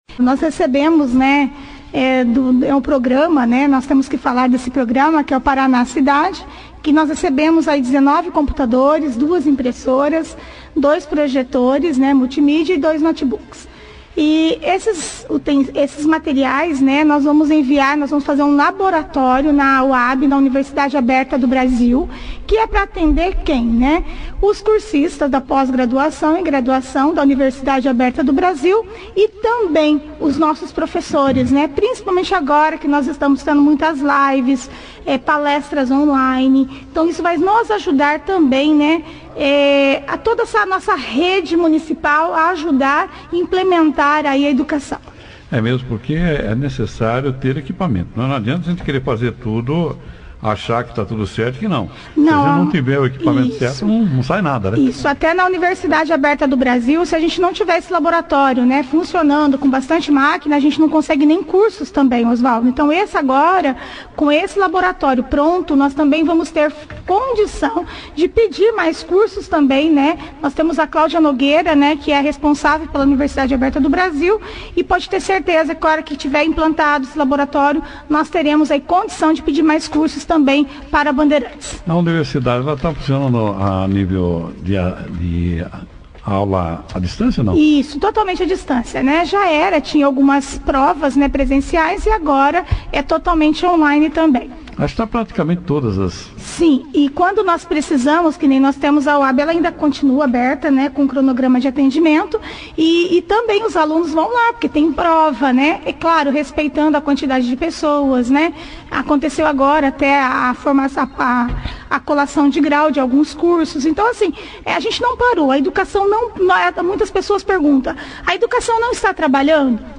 A Secretária de Educação de Bandeirantes, Valquiria Martins, participou da 2ª edição do jornal operação Cidade desta segunda-feira, 17/08, falando detalhes da distribuição sobre Os 19 computadores,2 impressoras, 2 projetores data show e 2 notebooks que o laboratório de Informática recebeu na última, através do deputado Luiz Claudio Romanelli, que atenderá os alunos que fazem os cursos de graduação e especialização no Polo da UAB de Bandeirantes,